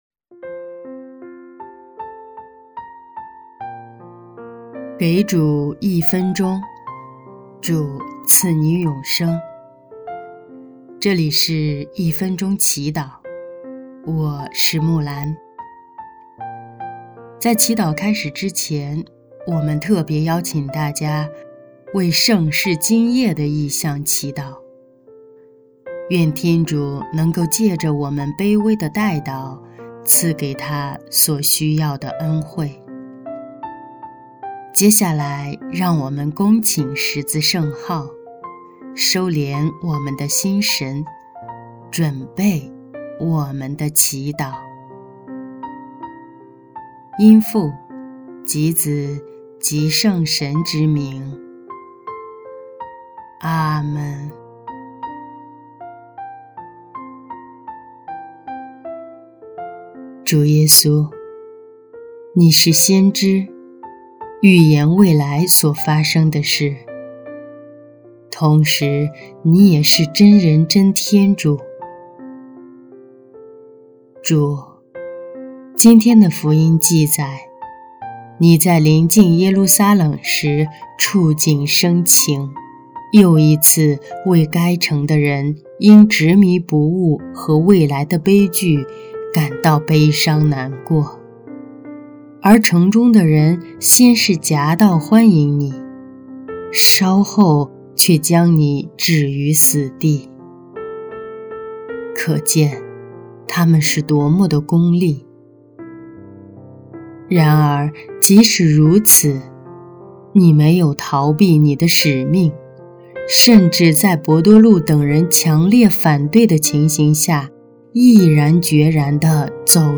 音乐：第一届华语圣歌大赛参赛歌曲《走上侍奉路》